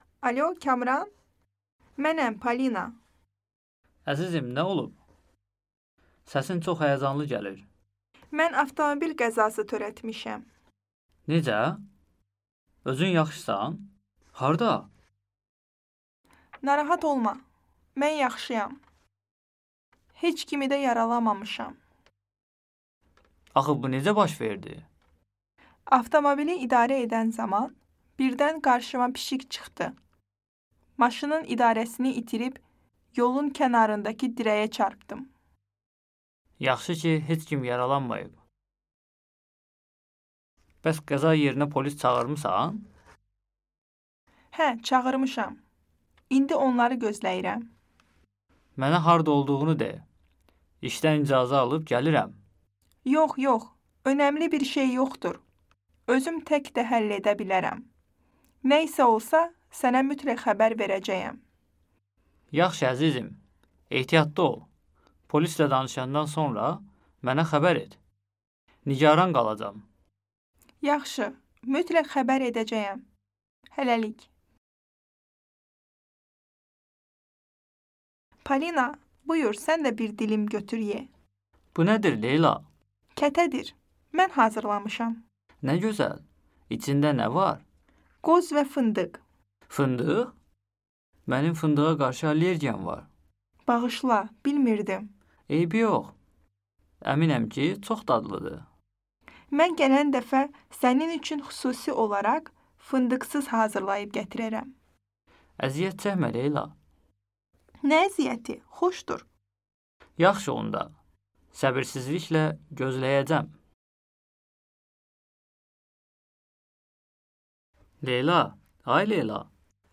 事故やアレルギー、求職についての会話を学びます。